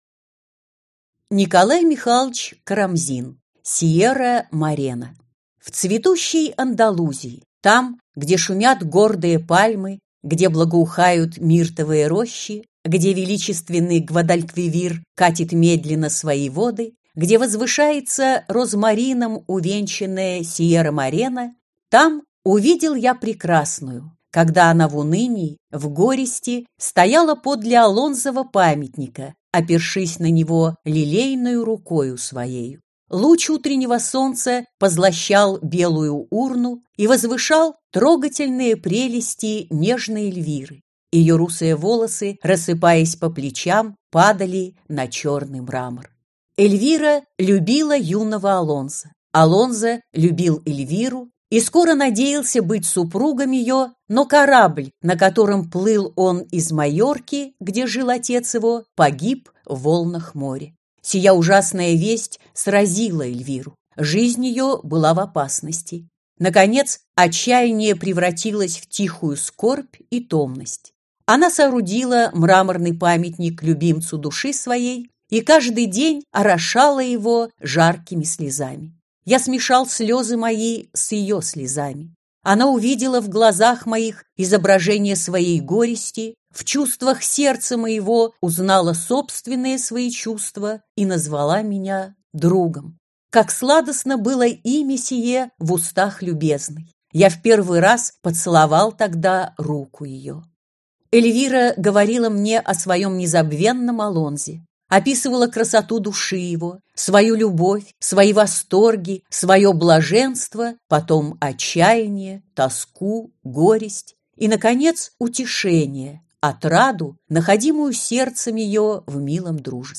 Аудиокнига Русская фантастика (сборник) | Библиотека аудиокниг
Прослушать и бесплатно скачать фрагмент аудиокниги